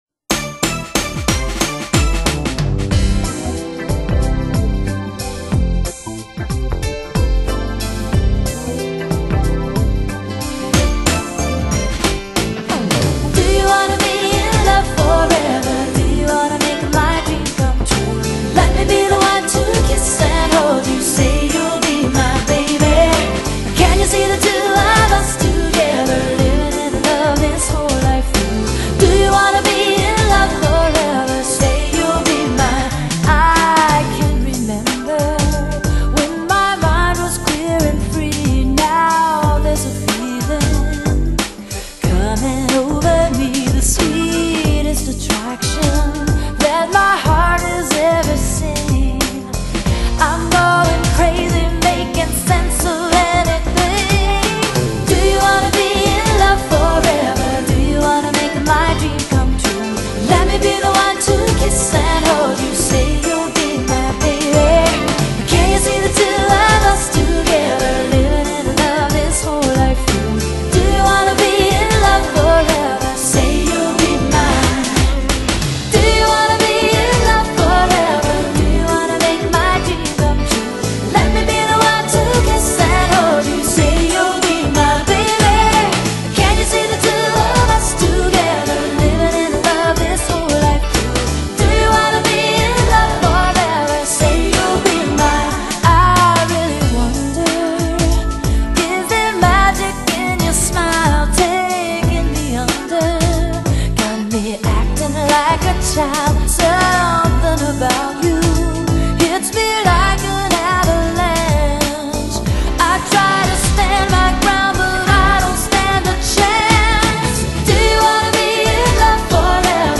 【美國福音歌手】